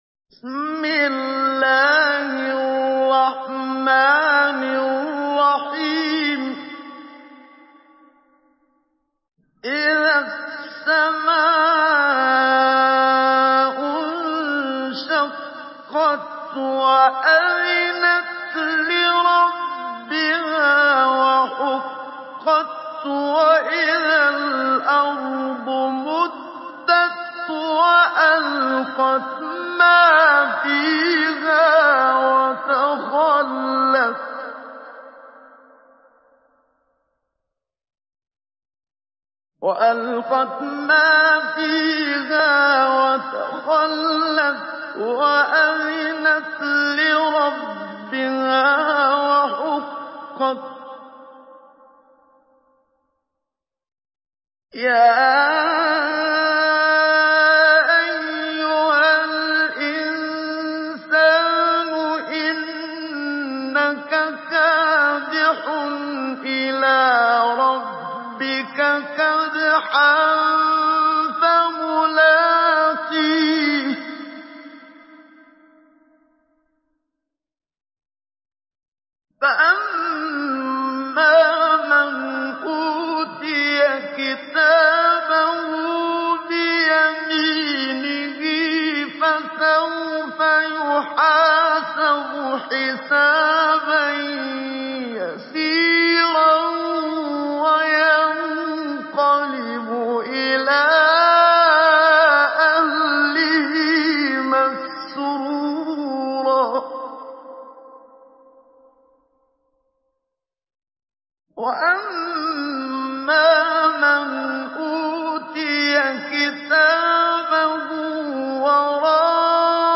Surah الانشقاق MP3 in the Voice of محمد صديق المنشاوي مجود in حفص Narration
Surah الانشقاق MP3 by محمد صديق المنشاوي مجود in حفص عن عاصم narration.